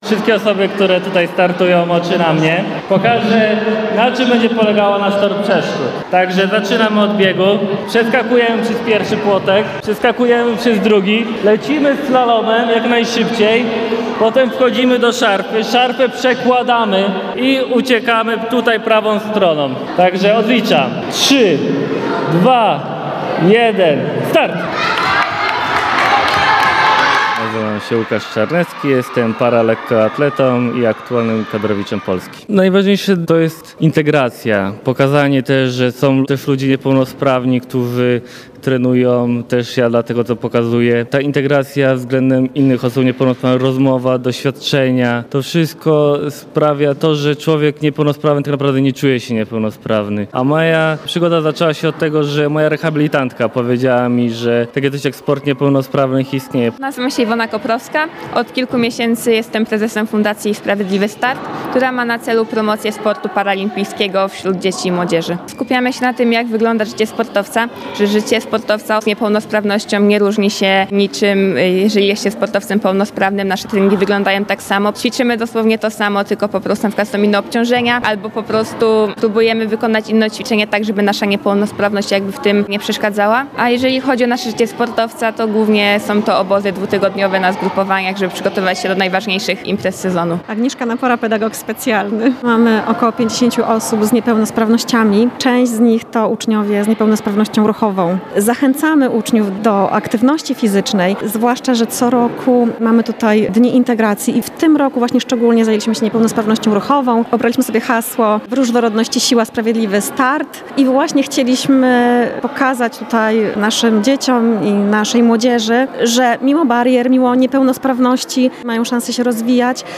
Niepełnosprawni profesjonalni lekkoatleci przekonywali dziś (03.04) w Szkole Podstawowej nr 6 w Puławach, że różne dysfunkcje nie dyskwalifikują z kariery sportowej. To element Dni Integracji organizowanych w tej placówce od kilku lat.